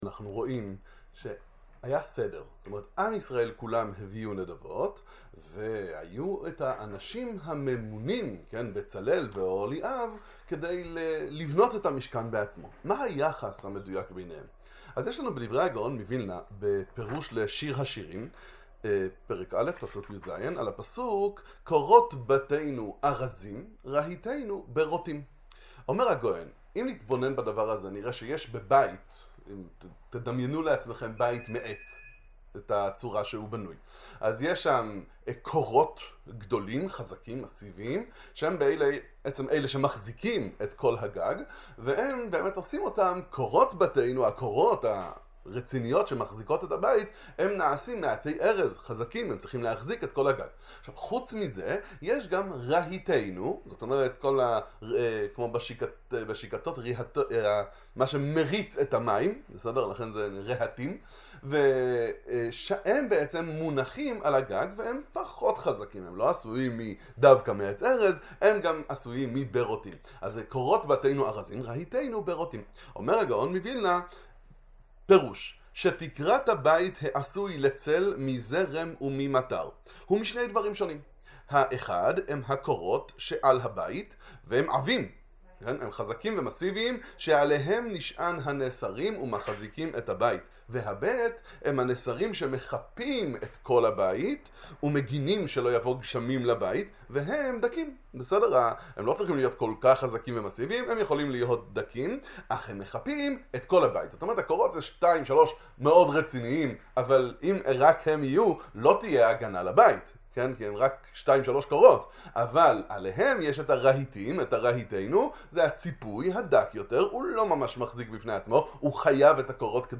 הקורות של המשכן והחיפוי – צורת הבניה של עם ישראל – דבר תורה קצר לפרשת ויקהל מדברי הגאון מוילנה